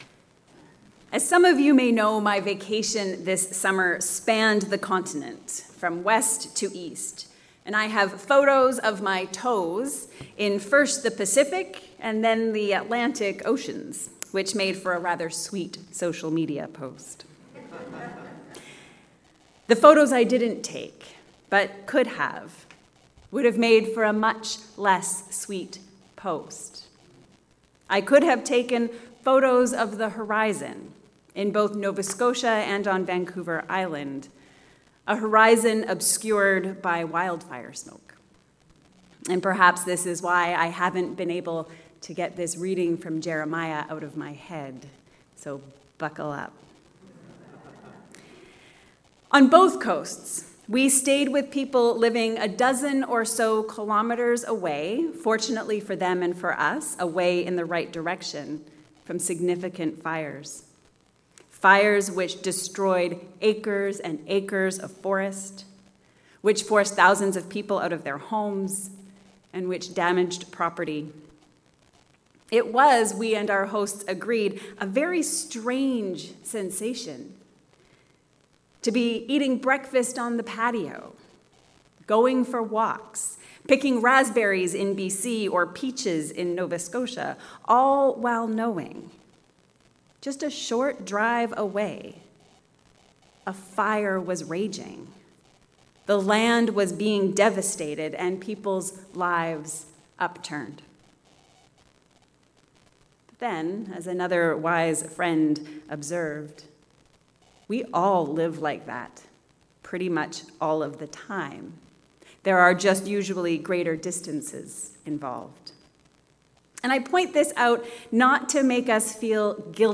Facing devastation. Clinging to hope. A sermon on Jeremiah 4